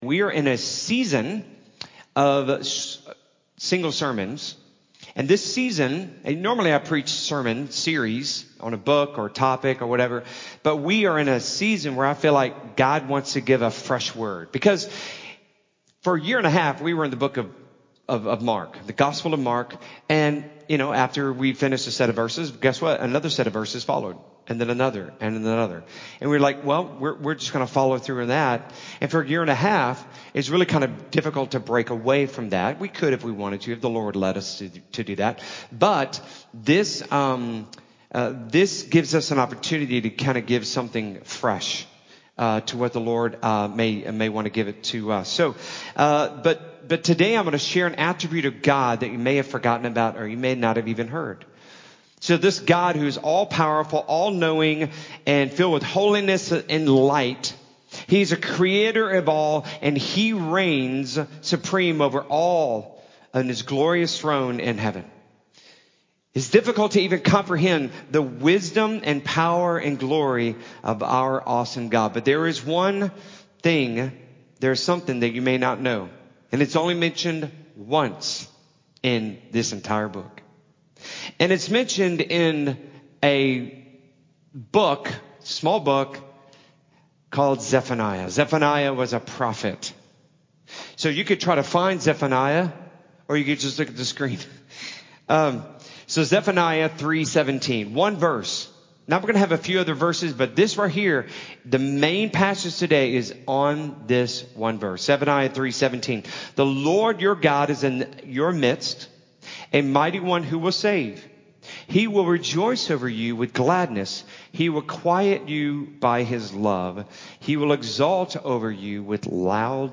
Single Sermons